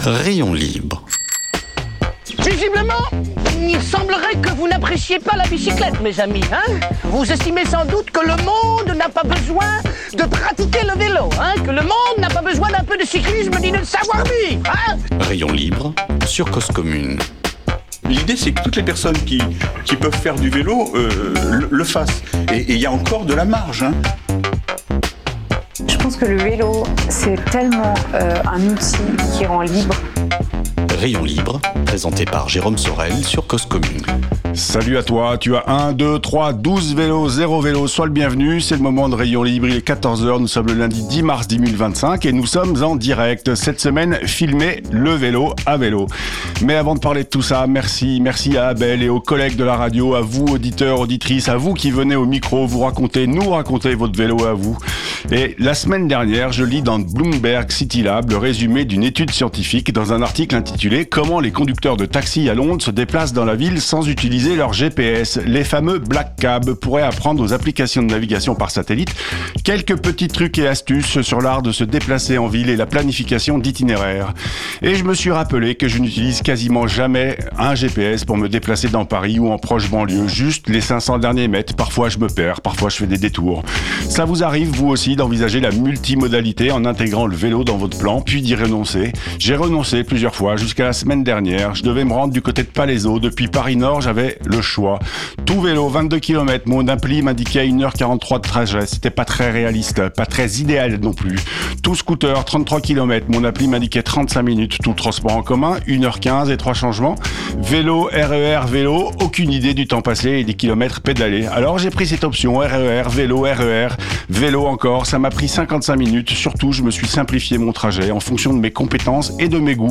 En plateau